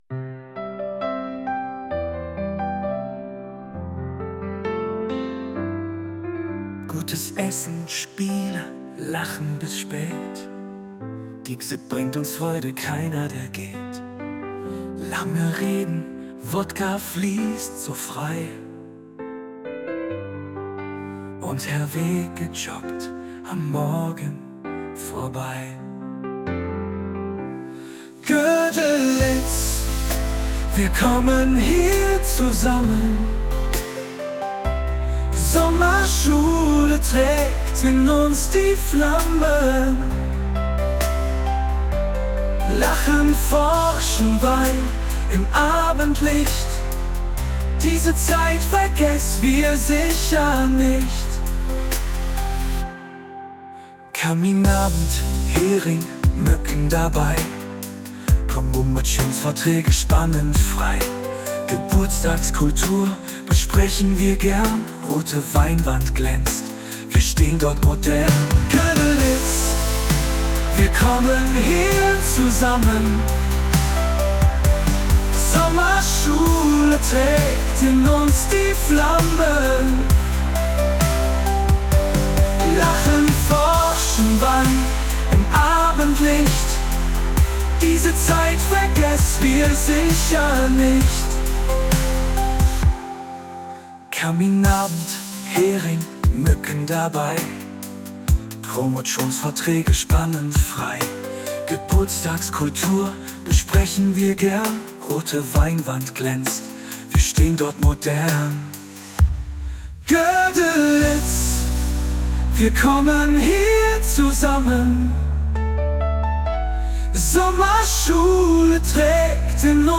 (Dieser Song wurde mit Suno AI (kostenlose Version) generiert.
Godelitz-Song-Ballade.mp3